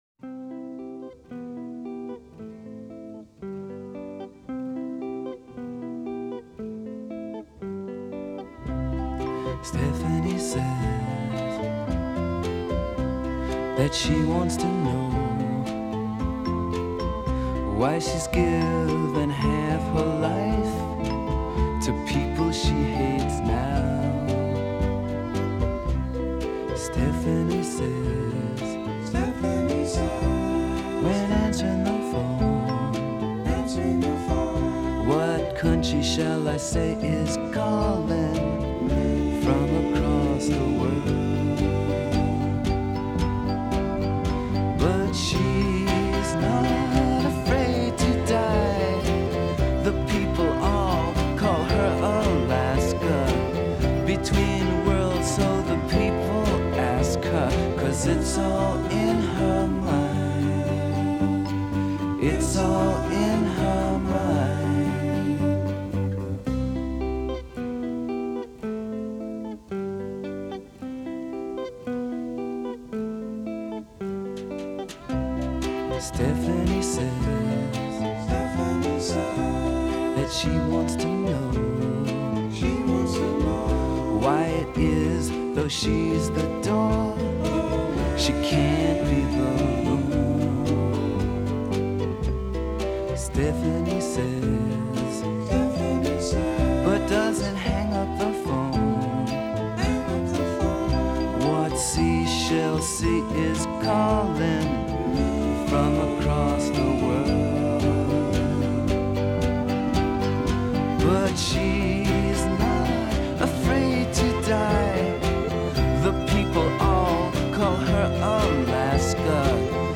rock music Art Rock